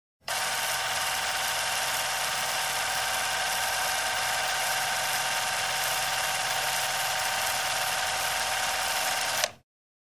Звуки пленки
Ещё один вариант с перемоткой плёнки проектора